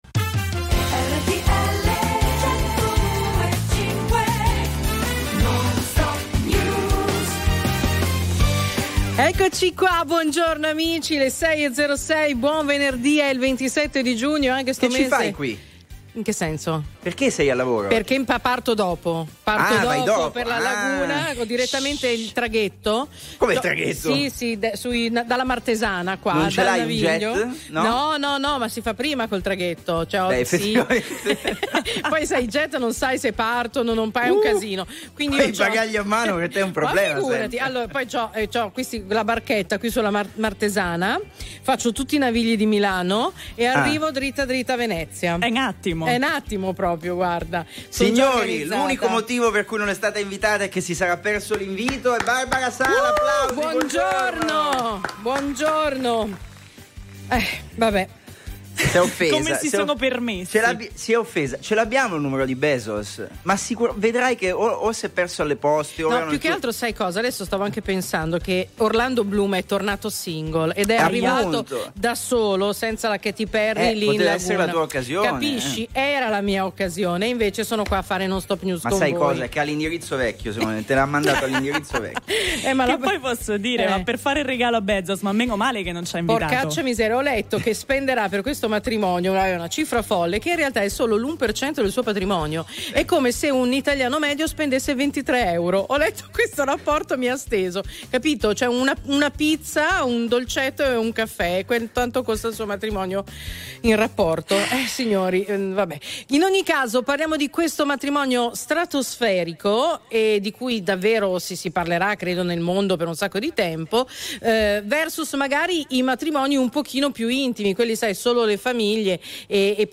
… continue reading 62 حلقات # Notizie quotidiane # Notizie d'affari # News Talk # Notizie # Notizie sportive # Notizie di tecnologia # RTL 102.5 # Mondo dello spettacolo